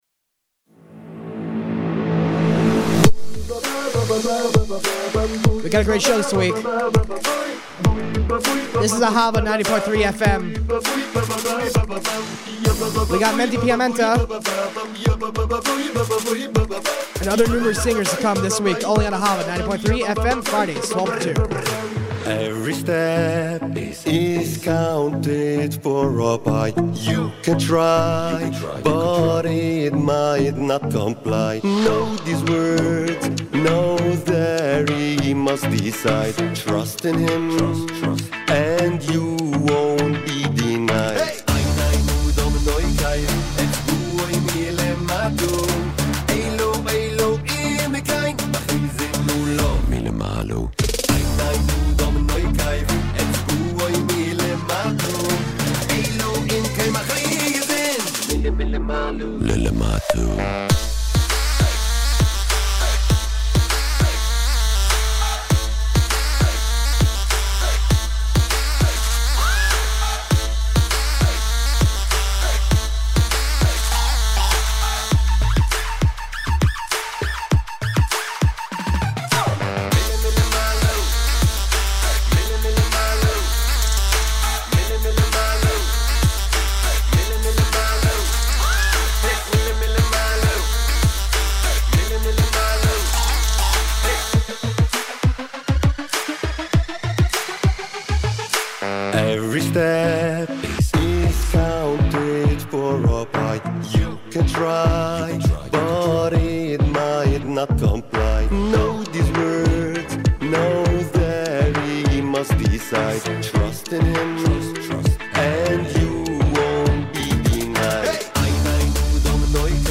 Play Rate Listened List Bookmark Get this podcast via API From The Podcast WE ARE AHAVA NY'S #1 JEWISH FM RADIO SHOW Join Podchaser to...